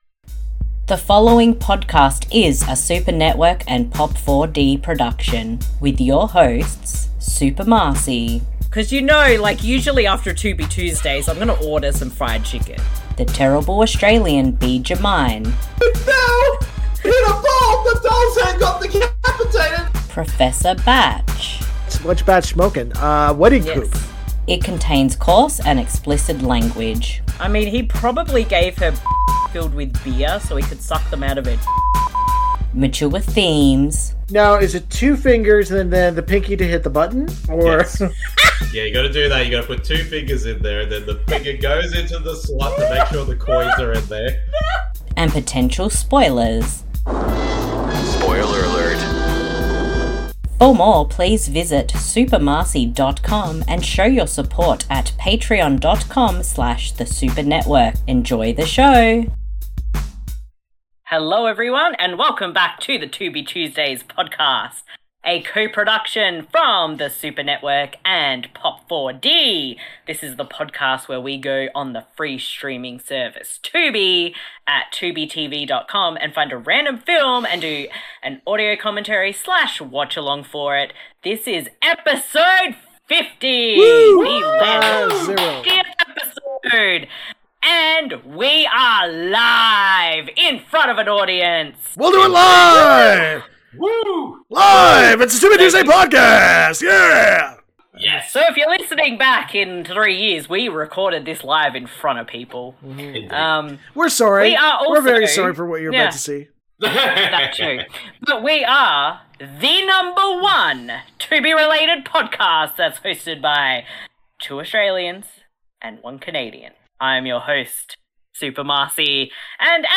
The Tubi Tuesdays Podcast Episode 50 Shark Attack 3: Megalodon (2002) Recorded Live On Discord